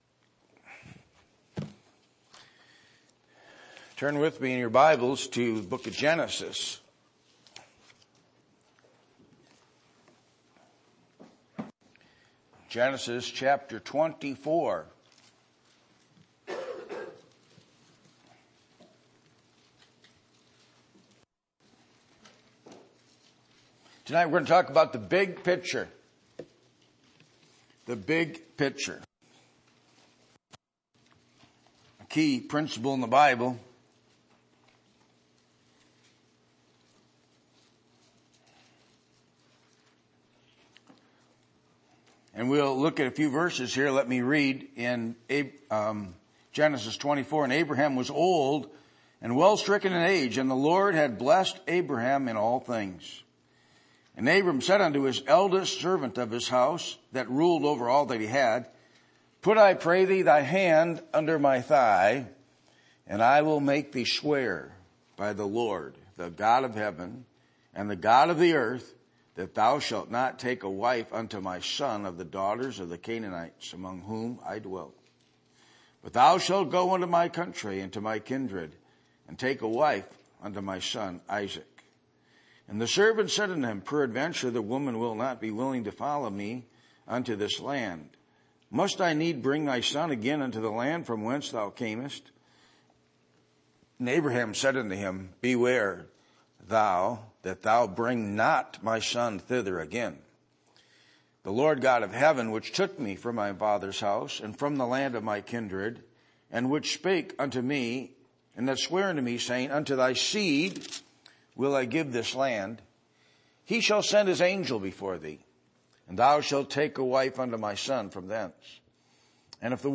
Passage: Genesis 24:1-67 Service Type: Sunday Evening %todo_render% « What Is The Purpose For Your Life?